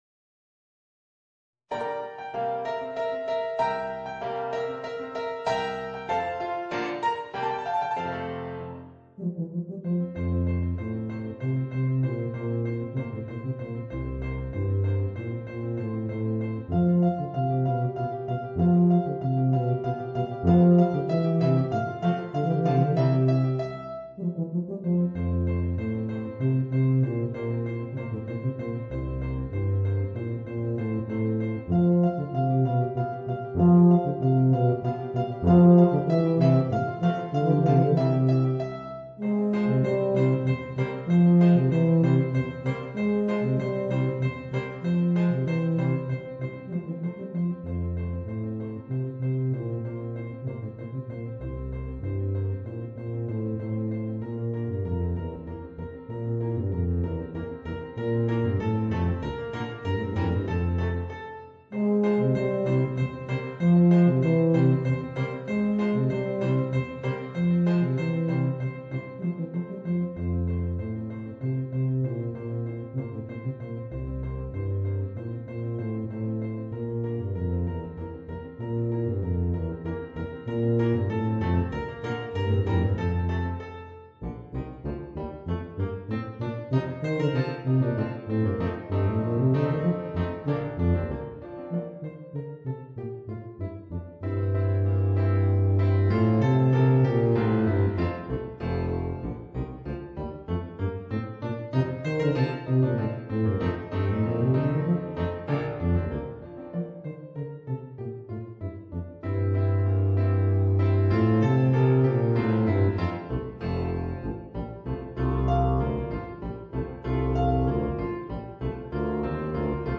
Voicing: Tuba and Piano